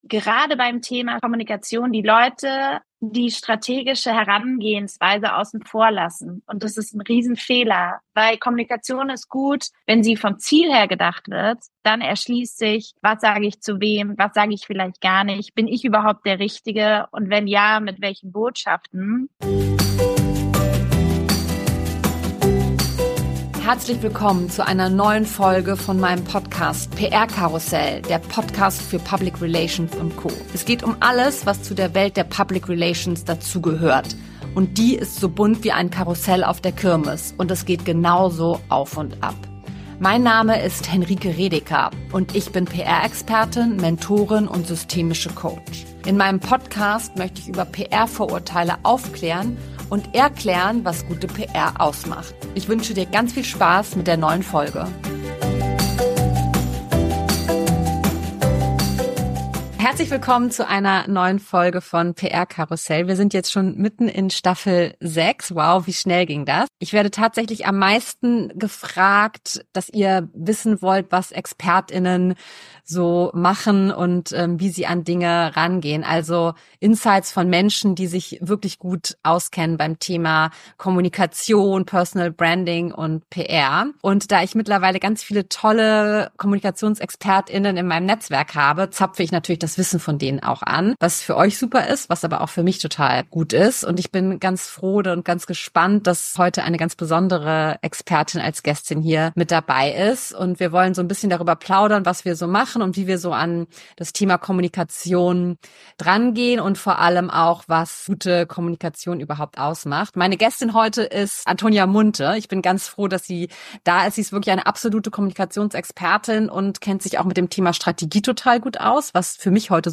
ein Gespräch unter Kolleg*innen